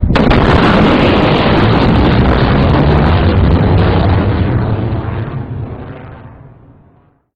Ein Machscher Kegel, der einen Beobachter überholt, wird als Knall wahrgenommen (Hörbeispiel
Sonic-boom-massive-sound.ogg